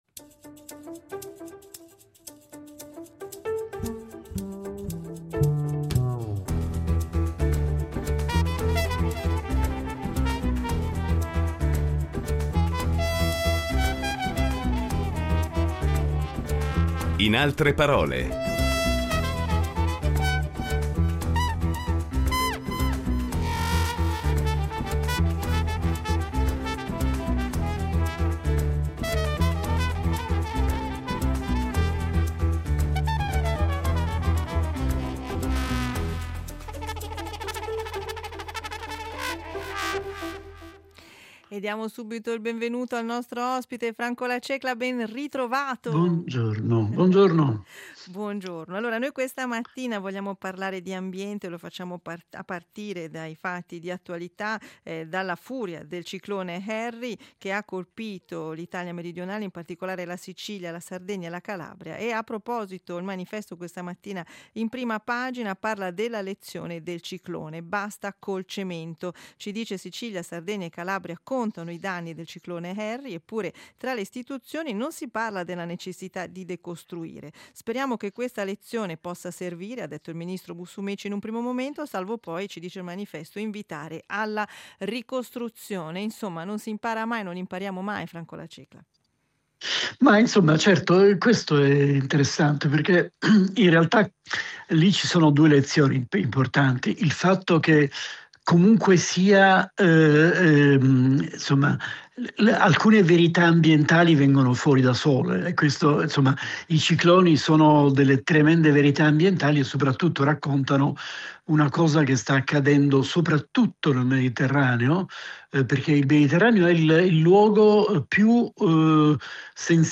Incontro con l’antropologo culturale e scrittore